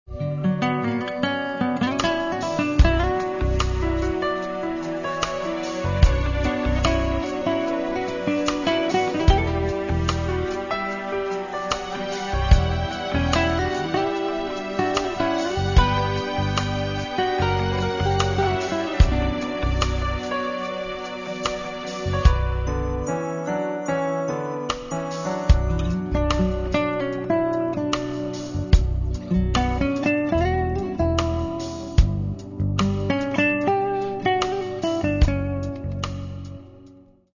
Catalogue -> Variety Art -> Instrumental Variations